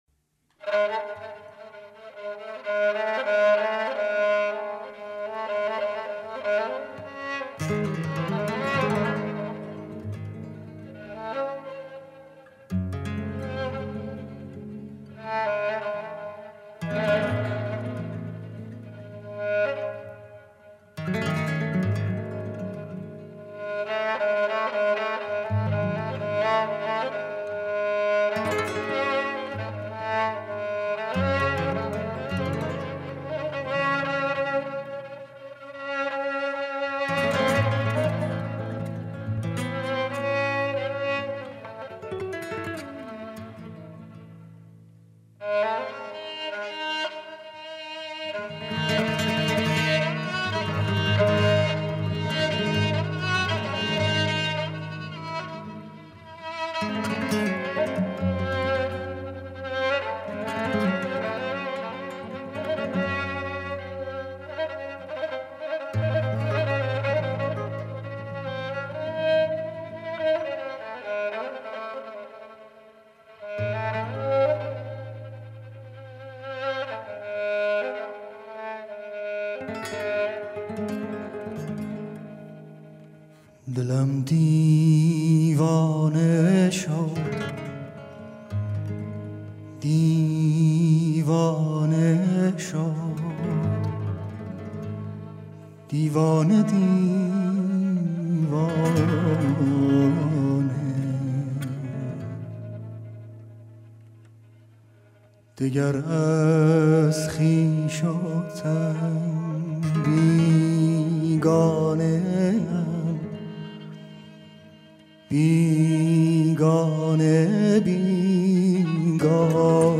хонандаи эронӣ